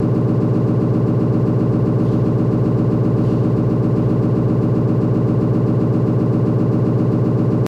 MRI Test .3 Guass Loop